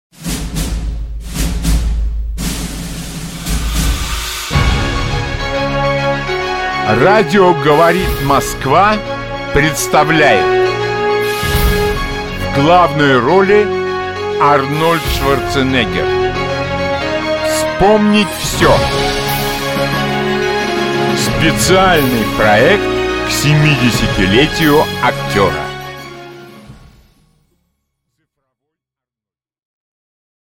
Аудиокнига Цифровой Арнольд | Библиотека аудиокниг